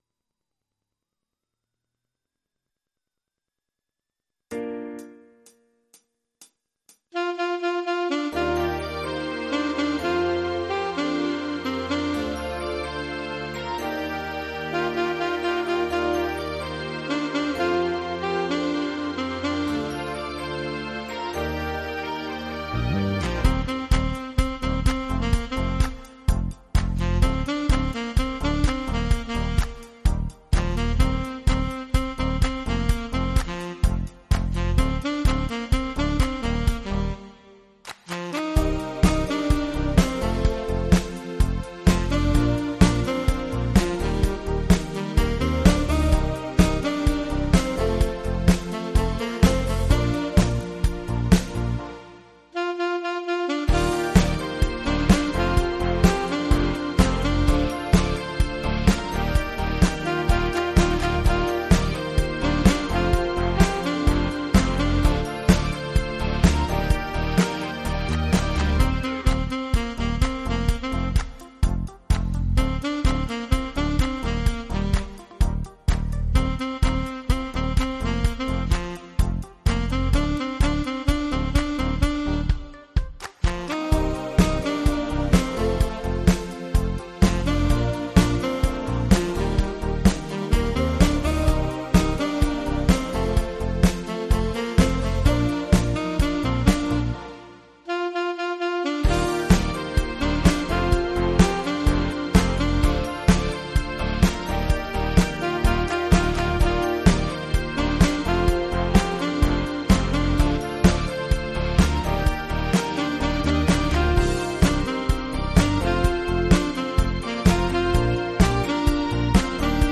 MIDI Karaoke version
PRO MIDI INSTRUMENTAL VERSION